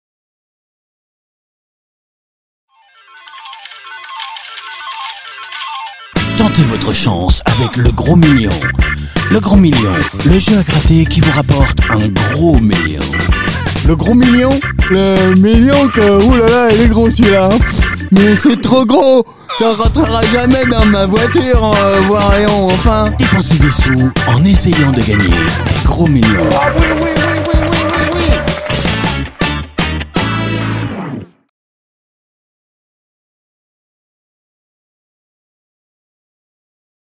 Fausses Pubs RADAR parodies publicités Fausses pubs